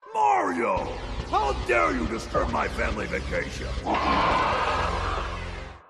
Description Bowser speaking and roaring in Super Mario Sunshine